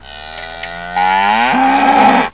COW05.mp3